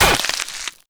SPLAT_Generic_04_mono.wav